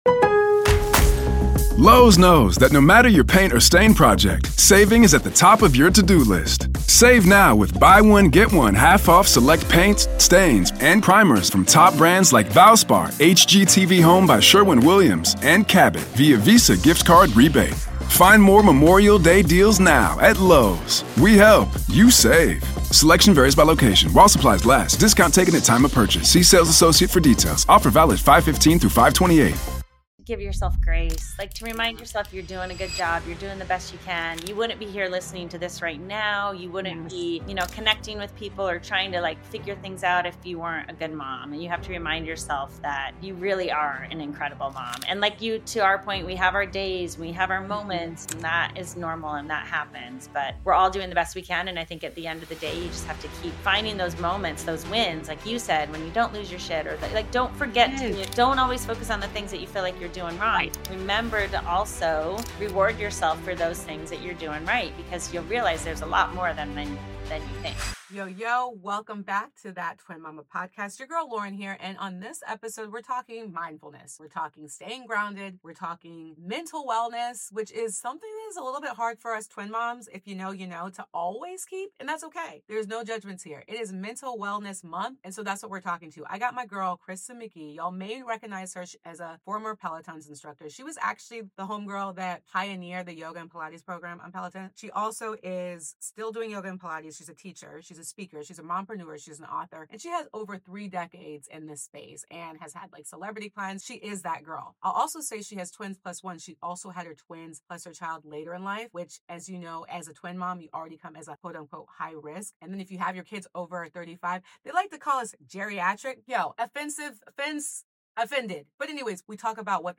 Her newest book, Neurodiversity and Technology, presents clear strategies to support neurodivergent children with healthy technology use. In this high-energy discussion